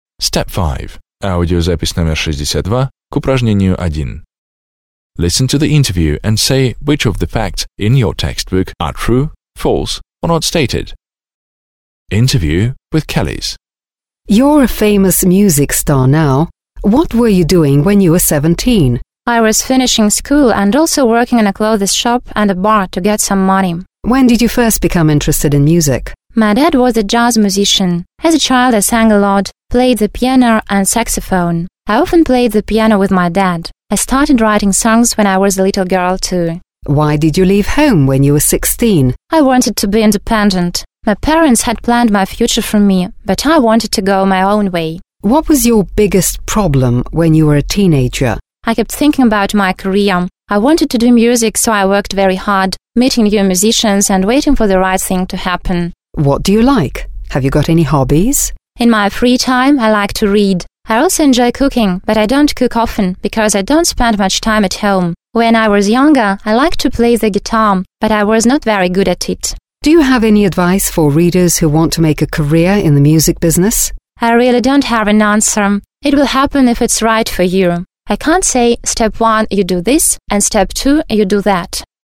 1. A. Listen to the interview with Kelis, an international music star, (62), and say which facts below are true, false or not stated.